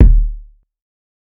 TC2 Kicks23.wav